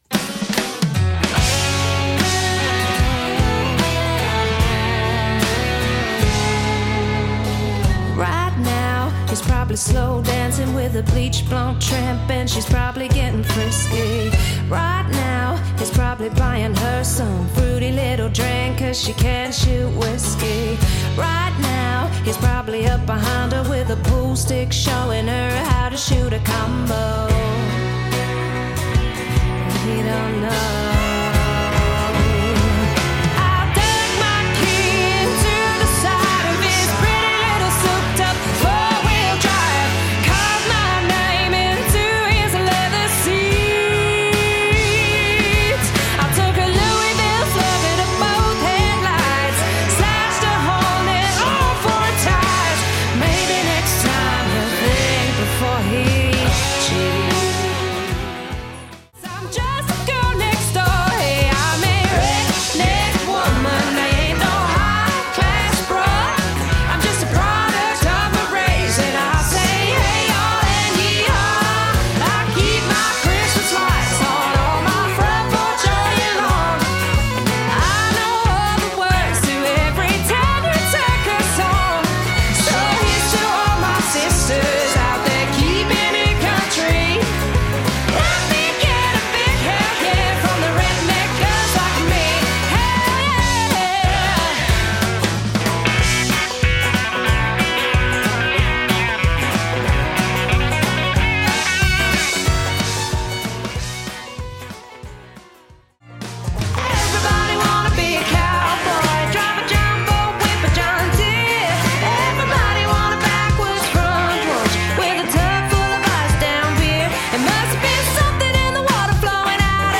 • Impressive male & female harmonies